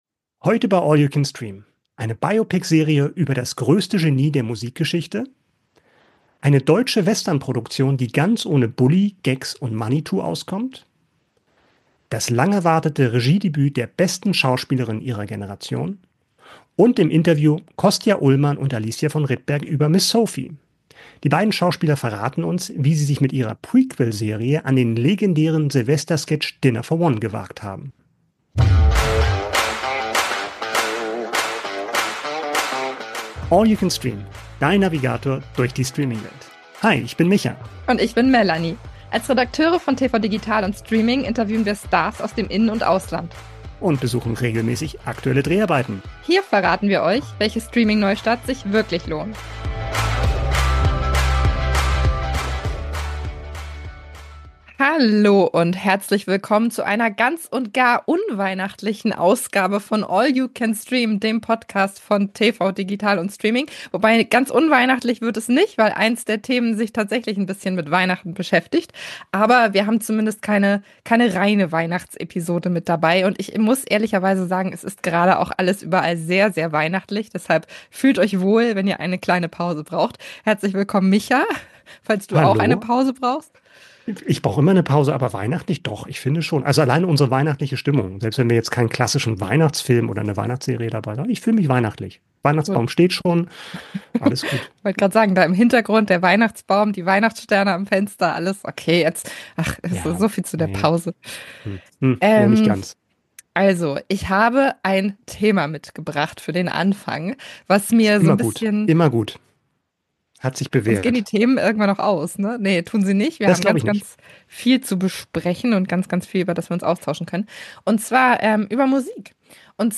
Die größten Streaming-Highlights von Mitte bis Ende Dezember. UND: unser Interview mit Kostja Ullmann und Alicia von Rittberg über ihre Serie "Miss Sophie" (Prime Video)